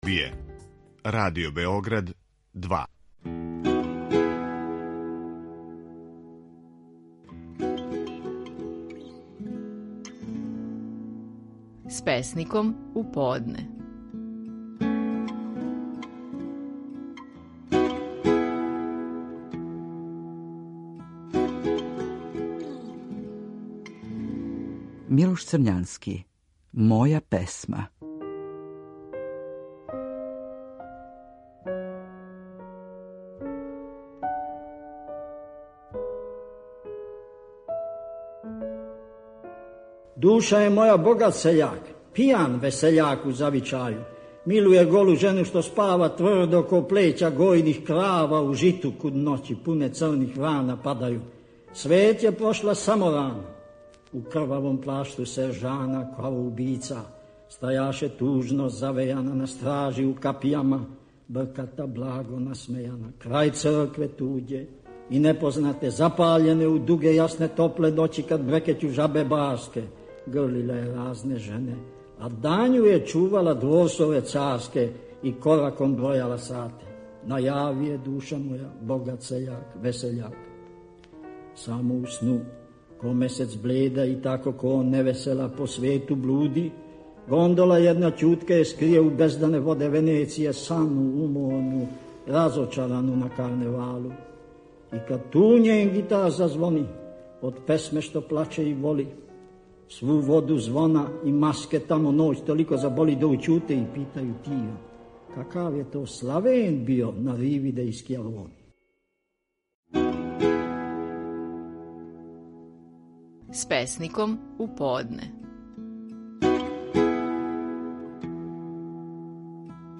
Стихови наших најпознатијих песника, у интерпретацији аутора.
„Моја песма" је назив стихова Милоша Црњанског које ћемо чути у његовој интерпретацији.